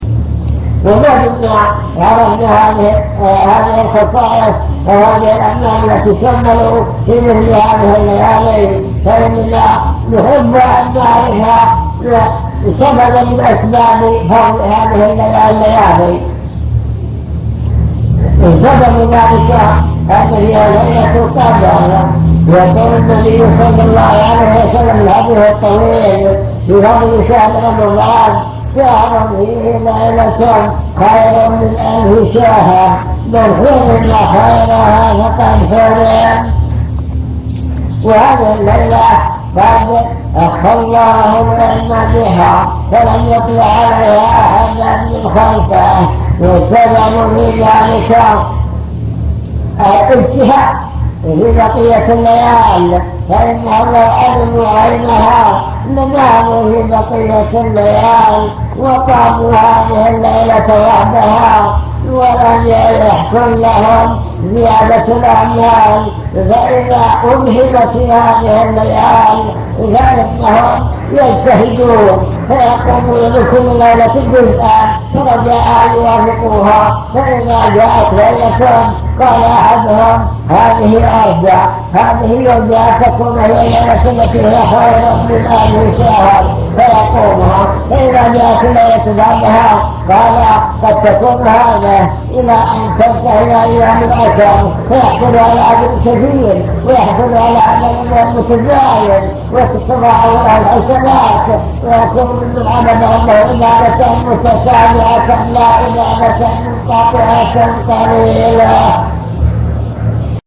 المكتبة الصوتية  تسجيلات - محاضرات ودروس  مجموعة محاضرات ودروس عن رمضان ليلة القدر